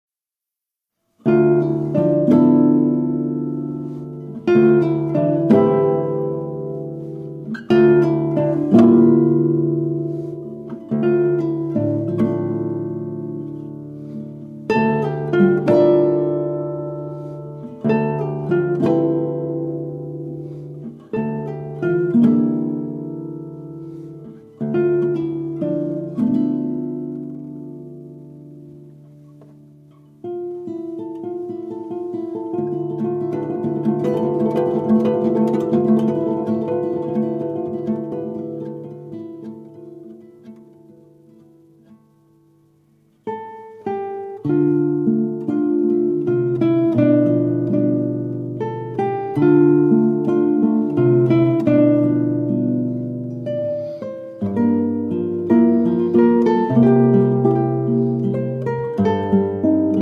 Duo works from South America
Guitar